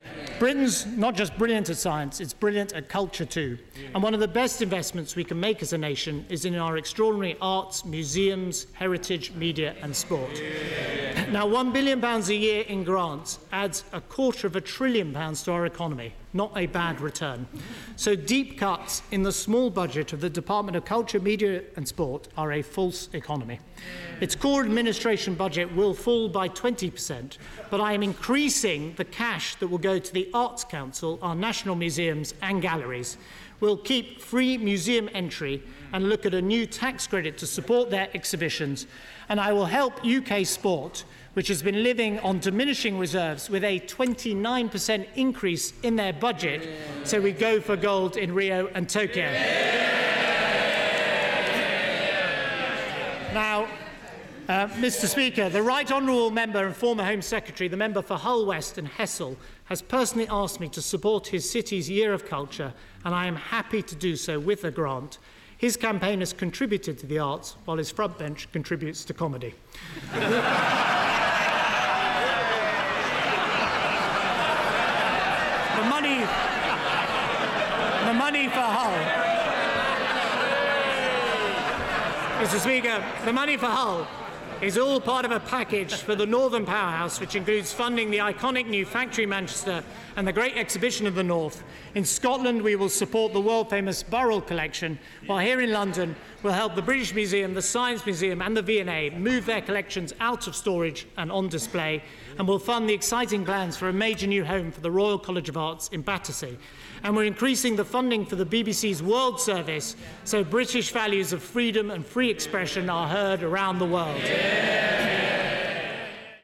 Chancellor George Osborne announcing funding for culture in his Autumn Statement 2015